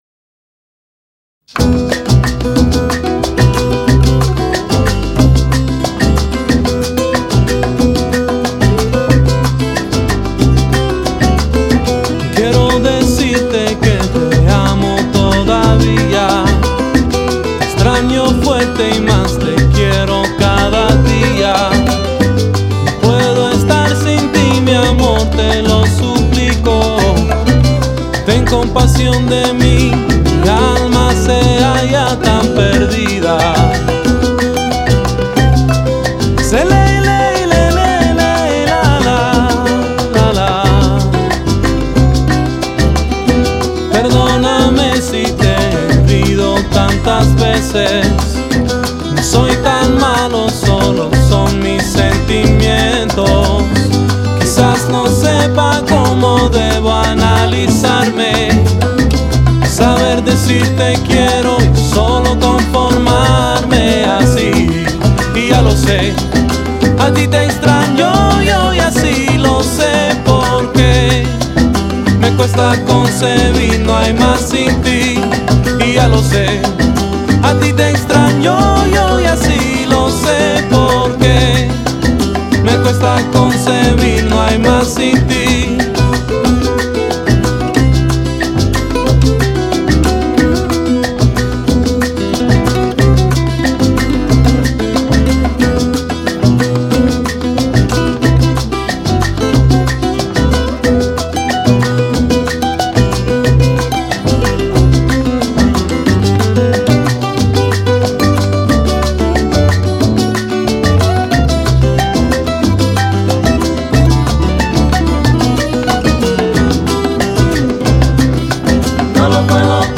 Тема не новая, но песня очень красивая и мелодичная.